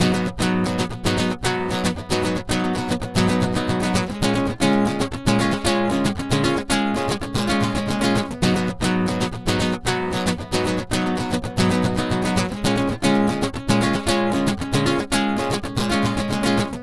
Ala Brzl 3 Nyln Gtrs-G#.wav